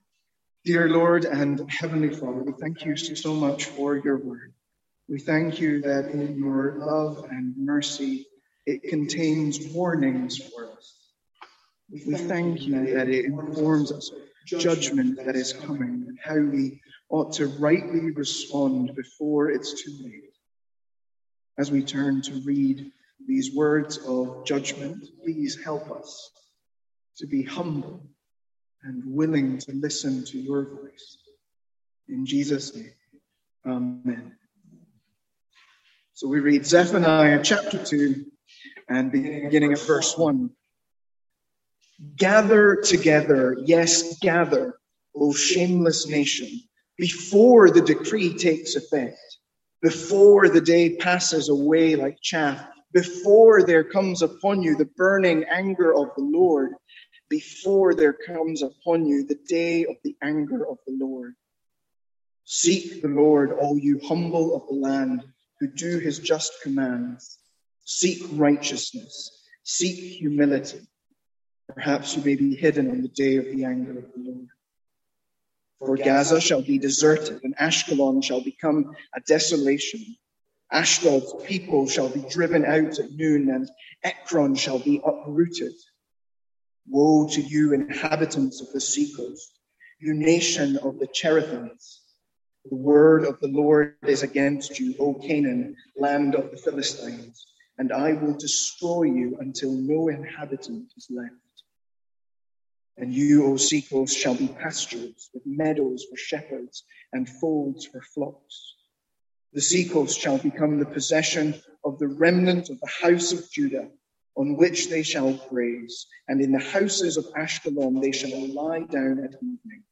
Sermons | St Andrews Free Church
From our evening series in Zephaniah.